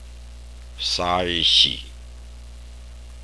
西勢 Sai1-si3